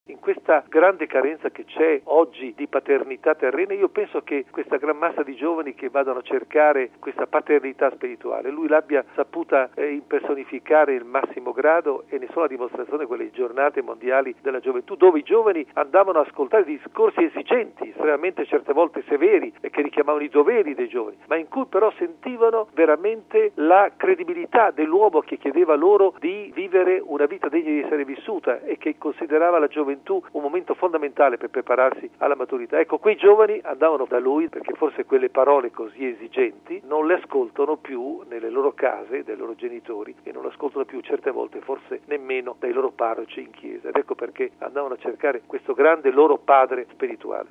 Sul perché di tanto seguito, da parte dei giovani, il commento del vaticanista e biografo di Giovanni Paolo II